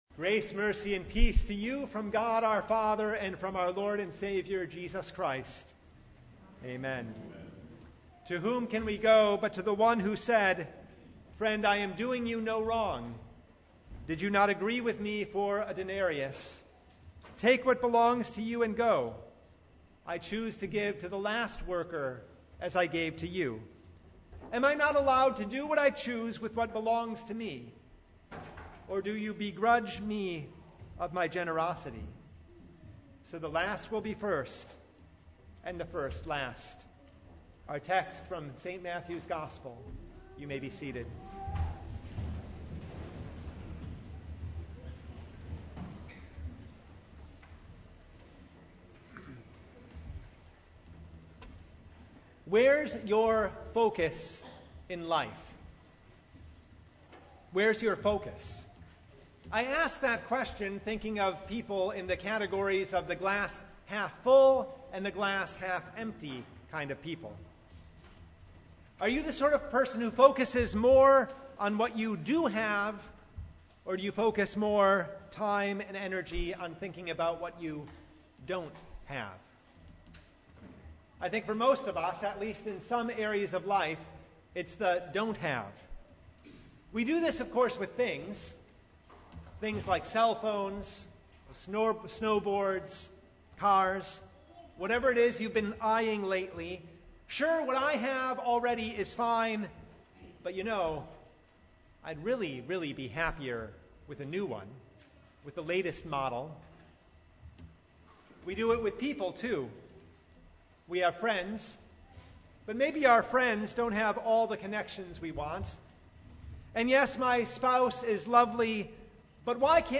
Matthew 20:1-16 Service Type: Sunday Our master is generous beyond belief .